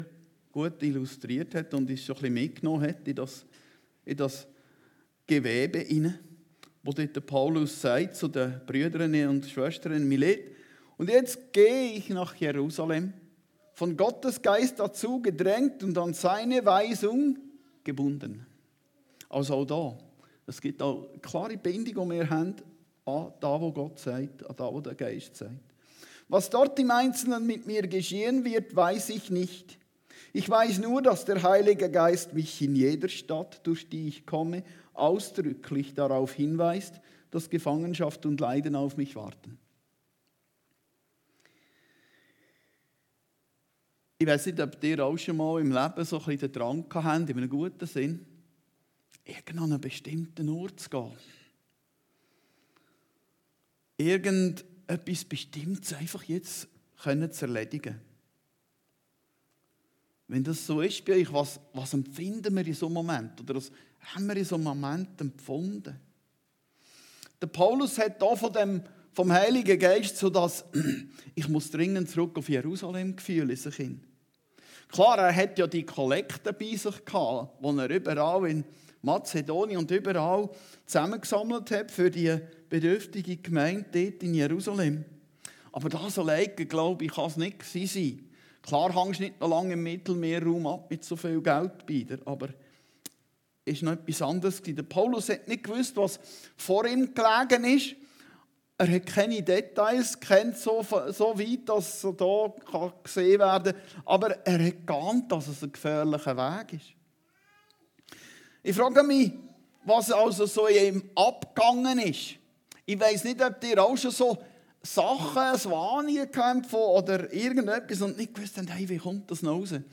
Predigten Heilsarmee Aargau Süd – Leiden für den Glauben an Jesus Christus